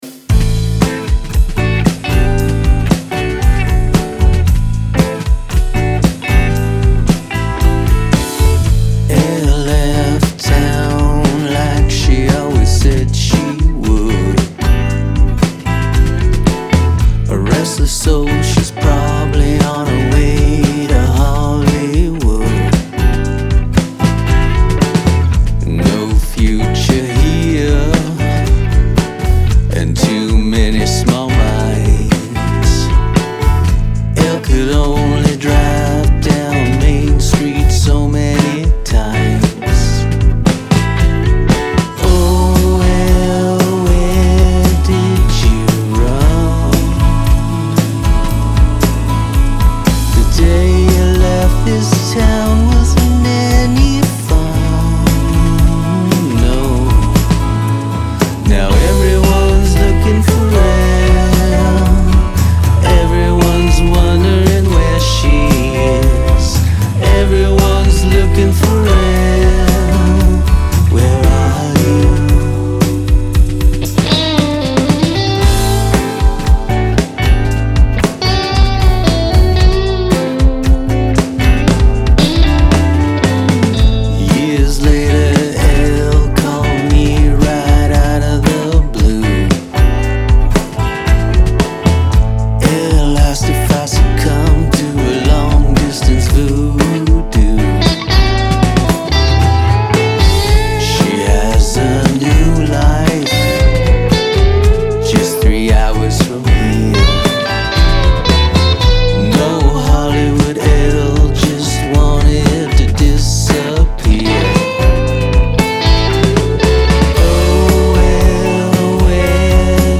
Looking For Elle [ Retro Rock / mixing practice ]
Ahoi, hab da eben mal reingehört .. da rumpelts bei mir ordentlich im Karton - also so Bass mäßig. Ach ja, was mir gleich nach den ersten paar Sekunden auffällt,- sag hast du Snare nicht etwas zu heftig komprimiert ? Da hört man nun die im Timing etwas wackeligen Ghost Notes extrem und ich empfinde die ausgesprochen störend.
Einerseits teils gute Grundaufnahmequalität, andererseits rumpeln und holpern sich die Musiker durch das Arrangement. Bandrauschen brauchte ich nicht, weil die Aussteuerung am Pult am Ende so weit unten war, dass so schon genug Rauschen zu hören ist.